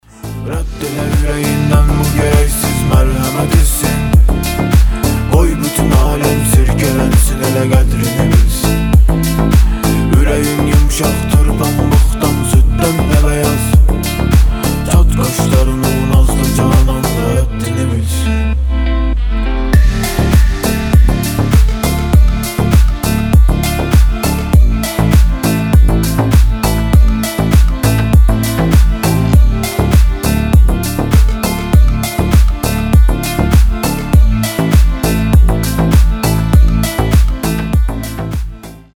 мужской вокал